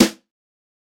Snare 015.wav